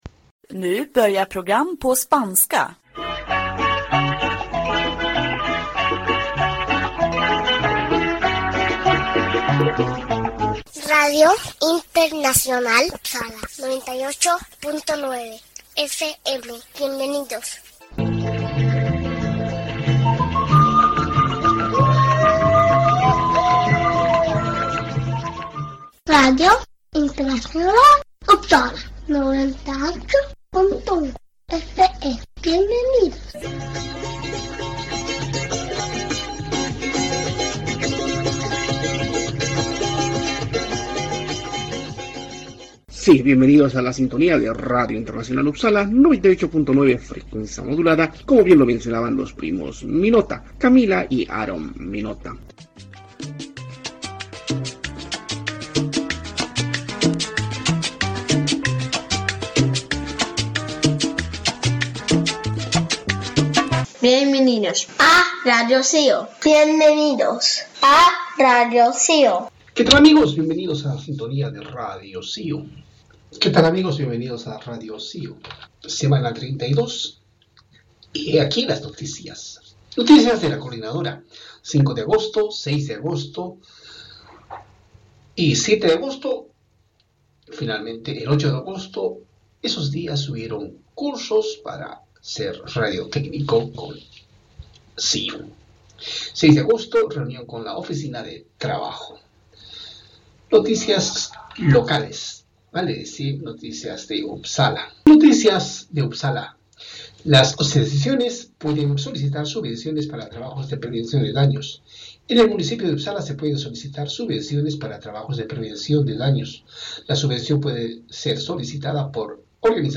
Sí, radio de cercanías en Uppsala se emite domingo a domingo a horas 18:30.